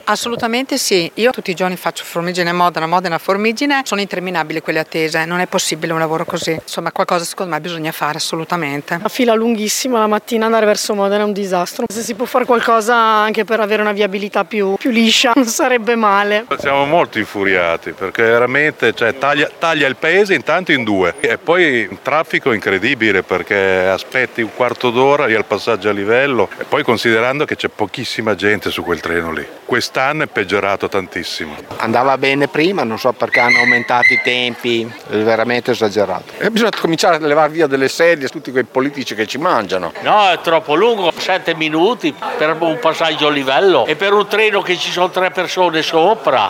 Ecco le parole dei formiginesi: